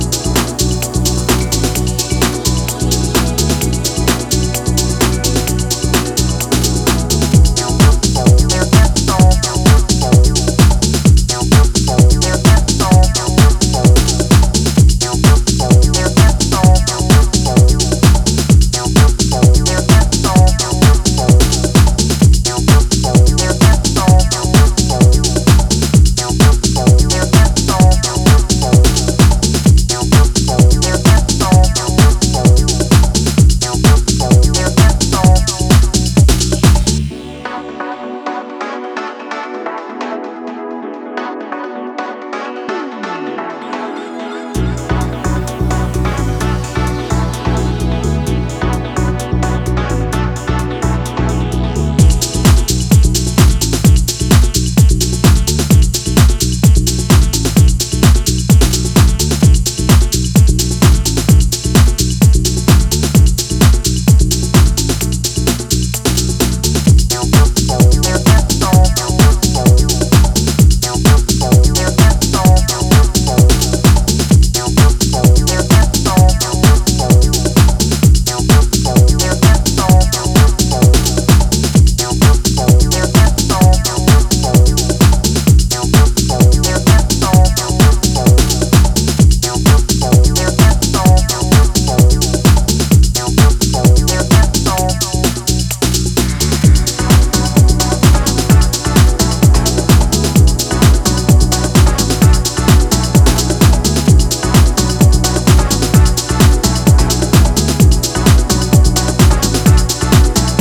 energy inducing